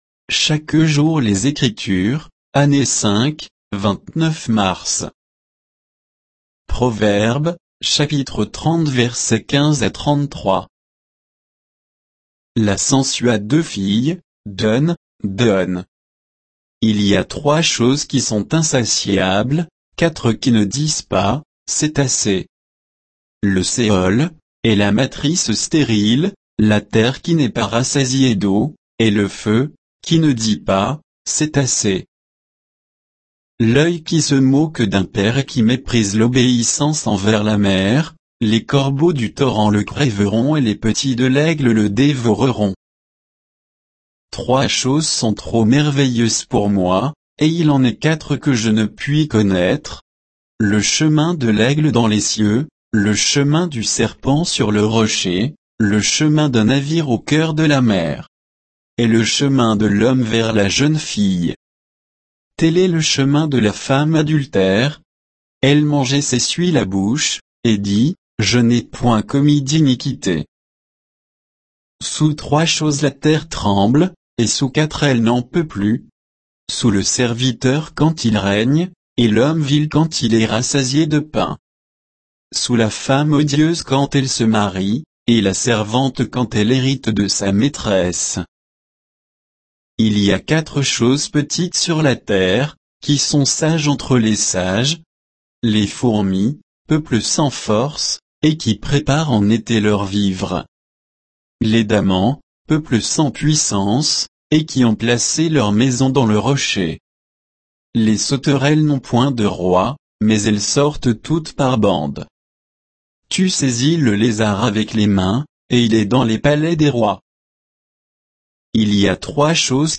Méditation quoditienne de Chaque jour les Écritures sur Proverbes 30, 15 à 33